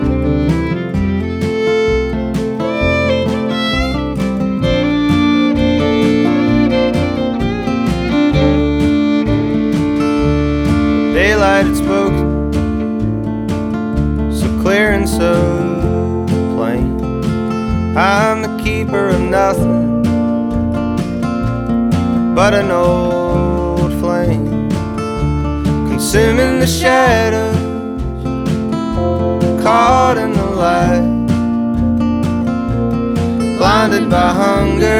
Жанр: Рок / Кантри